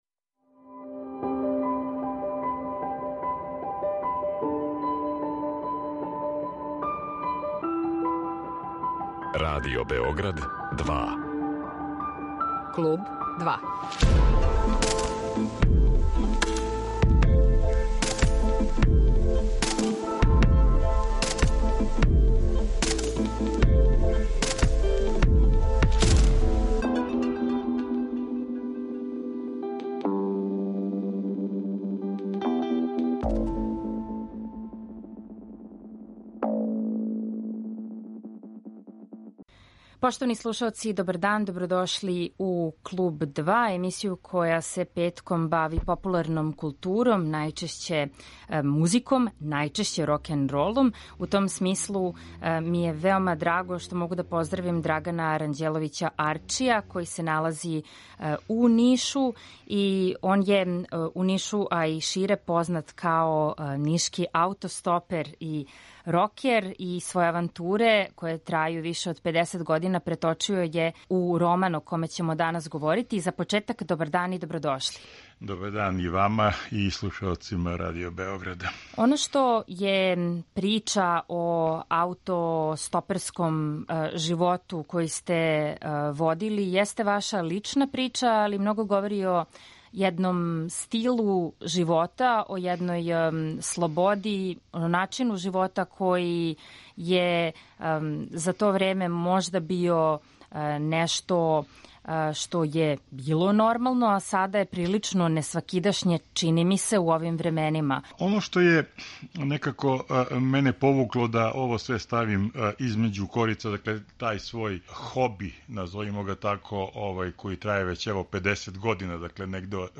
Наравно, у емисији ћемо слушати и музику коју гост воли и која се и помиње у његовој књизи, коју је објавио Простор за креативно деловање ДЕЛИ из Ниша.